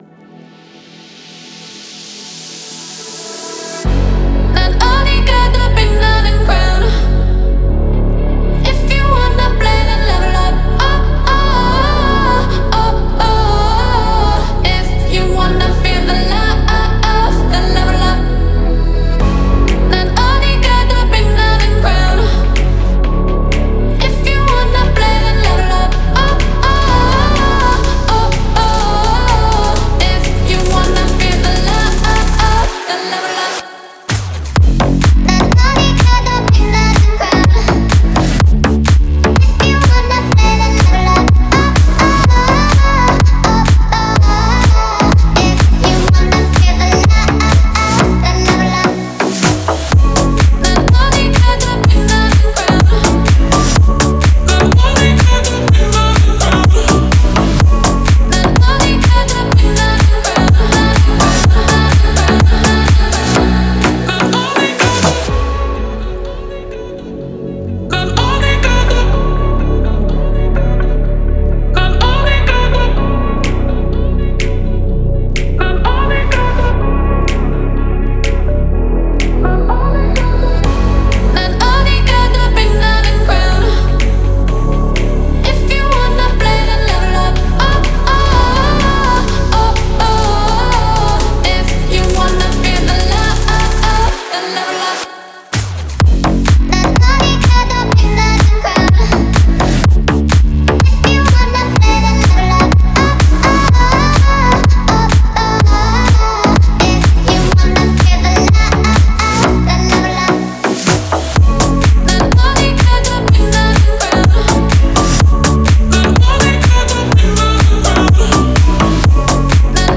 – это зажигательная композиция в жанре EDM